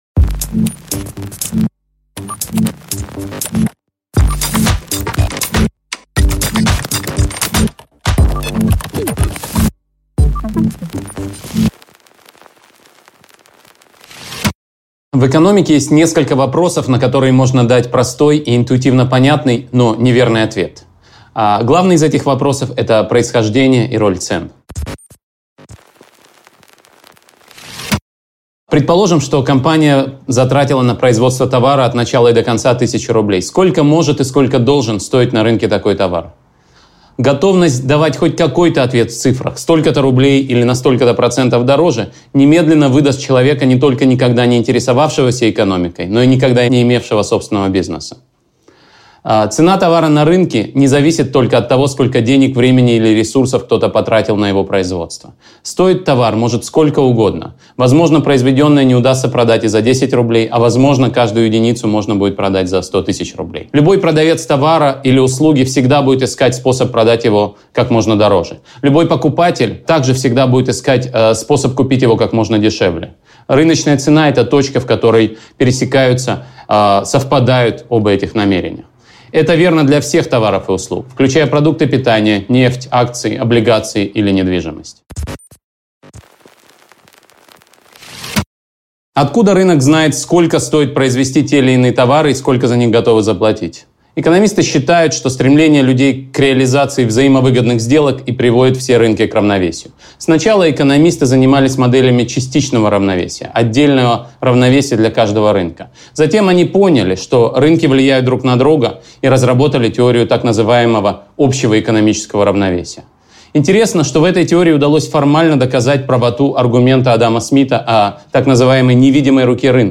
Аудиокнига Цена любого вопроса: что такое обмен и как работает рынок | Библиотека аудиокниг